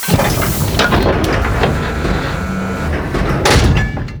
BoardShip.wav